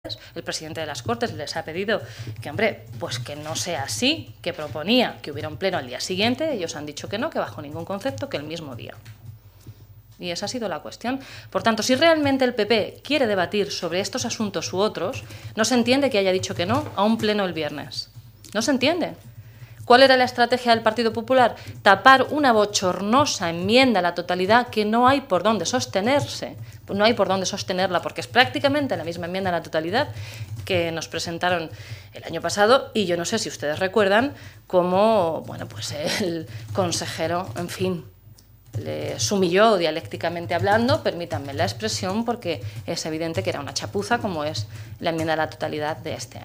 La portavoz del grupo Parlamentario Socialista en las Cortes de Castilla-La Mancha, Blanca Fernández, ha lamentado "las mentiras y la tergiversación que hace el PP del funcionamiento del parlamentario autonómico" y les acusa de montar un "paripé ante el nerviosismo que se vive en el seno de su partido".
Cortes de audio de la rueda de prensa